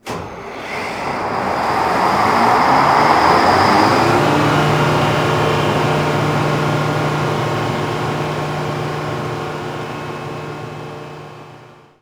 AtmosphericGenerator.wav